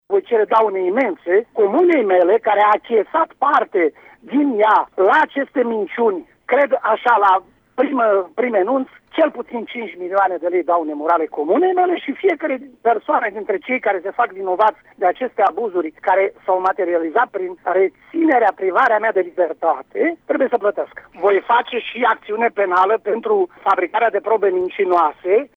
Bubău a avut câştig de cauză şi spune că va cere daune morale pentru acuzații nefondate și afectarea imaginii publice. În plus, spune fostul primar, se va adresa Parchetului cu plângere penală pentru fabricarea de probe mincinoase: